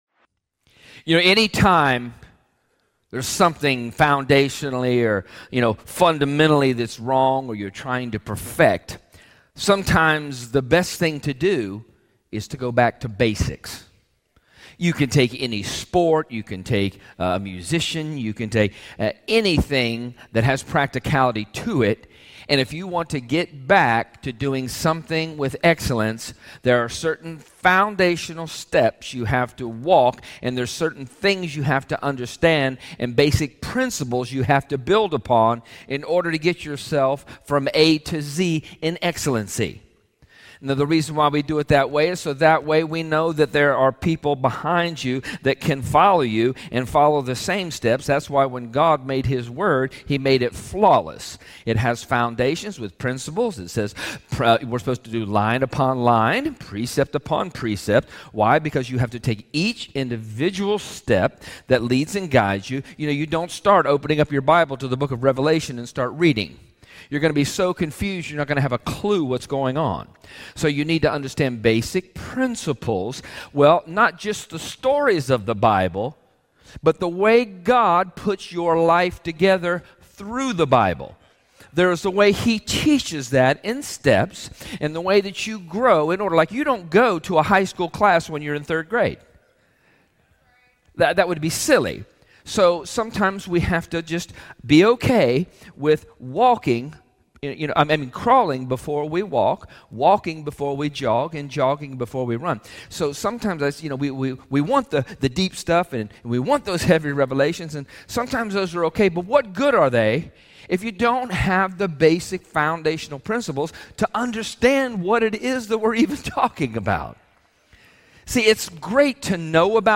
Sunday and Wednesday sermons from Glory To Him Church in Ozark, AL.